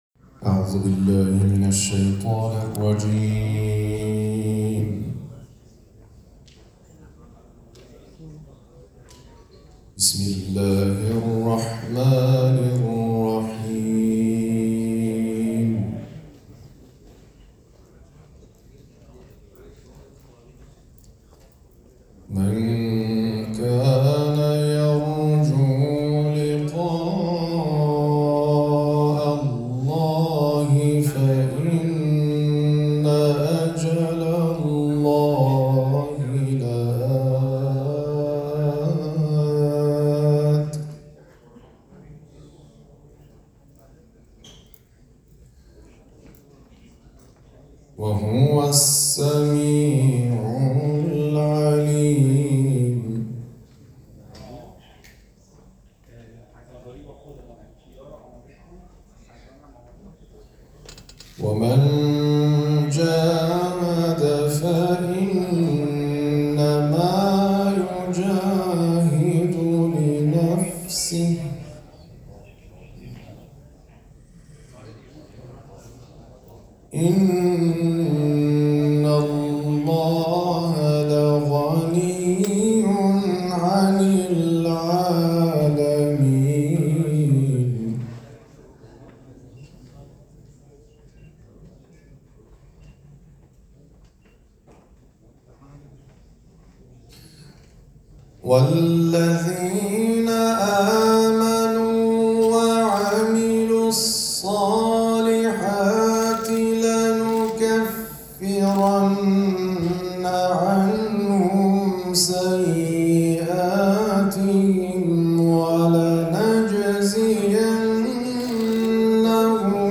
قاری شرکت کننده در چهل و پنجمین دوره مسابقات سراسری قرآن
تلاوت